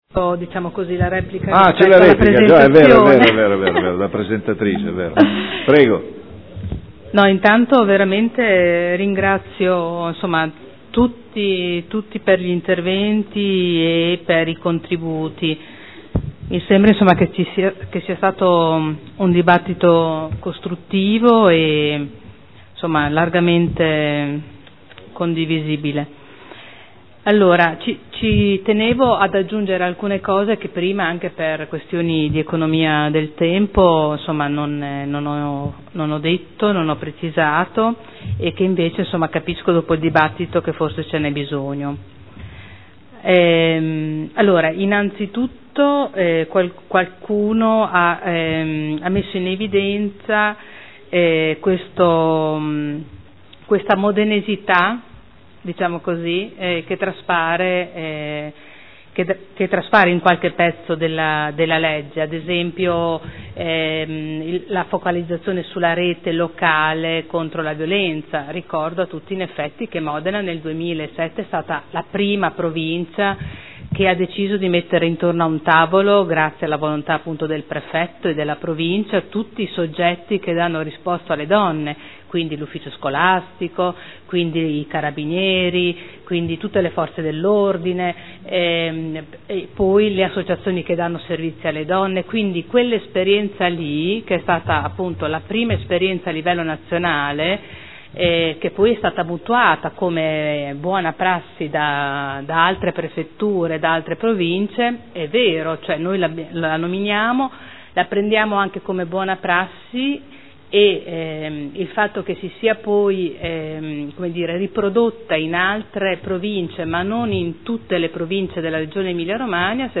Seduta del 27/05/2013. Replica. Dibattito.